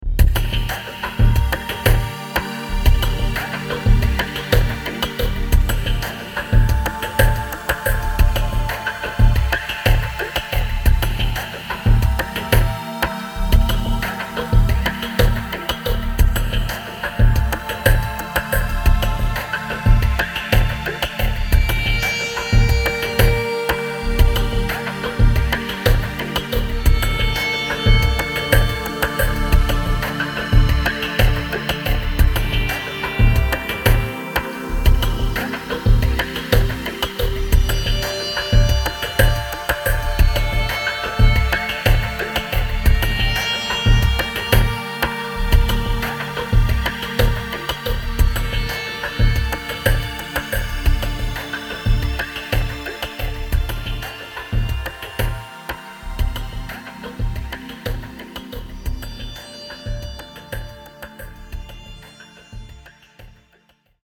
Here’s the rough mix without Cuttertone:
Cuttertone-Ambient-DRY.mp3